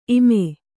♪ ime